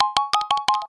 07303 marimbic simple advance ding
achievement advance alert ding marimba notification sound sound effect free sound royalty free Sound Effects